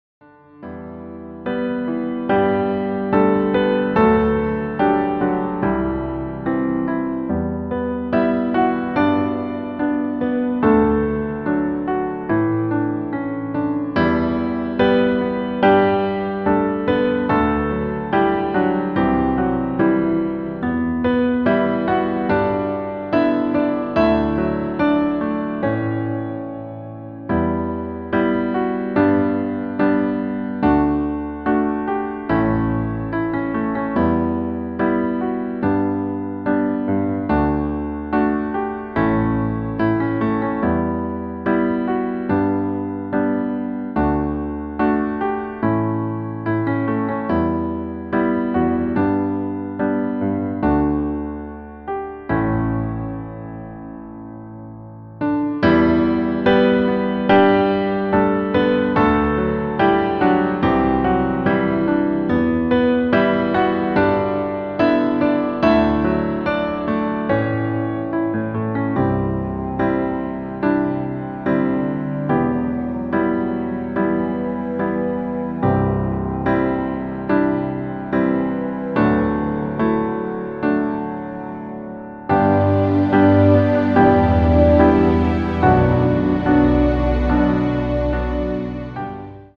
• Tonart: Am, Em, F#m
• Das Instrumental beinhaltet NICHT die Leadstimme